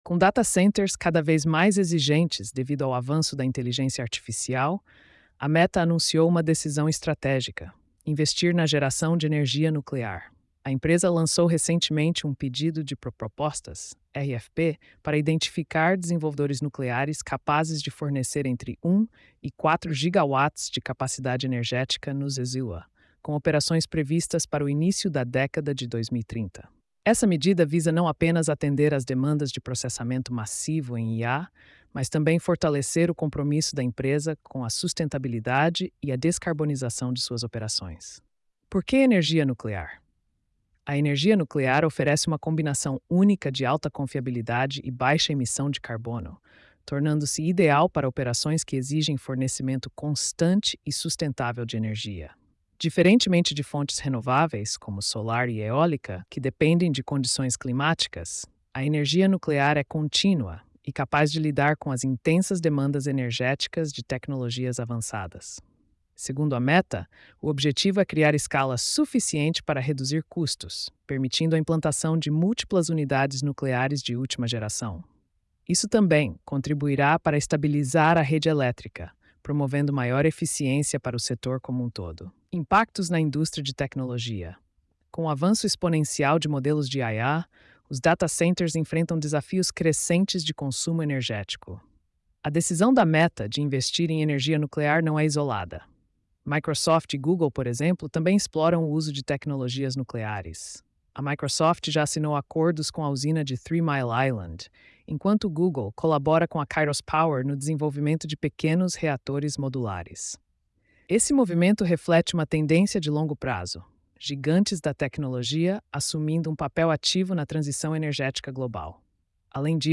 post-2653-tts.mp3